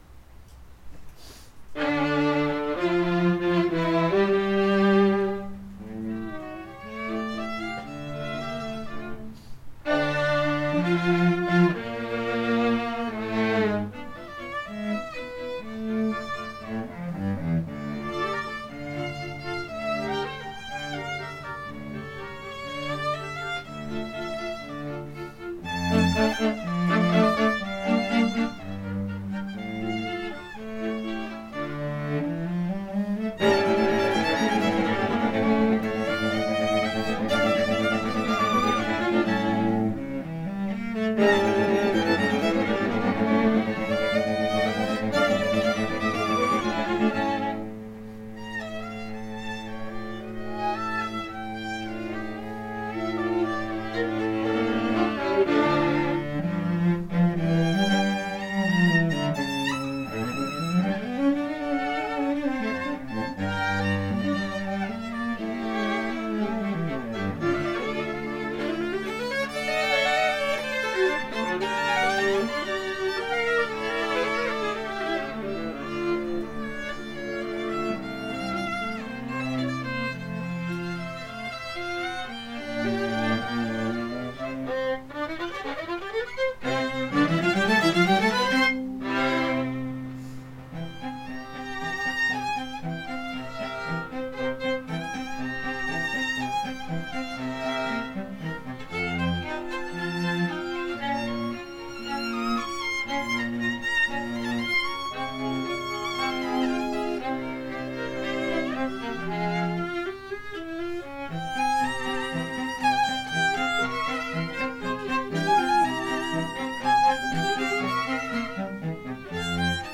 the students
Chamber Groups